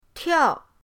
tiao4.mp3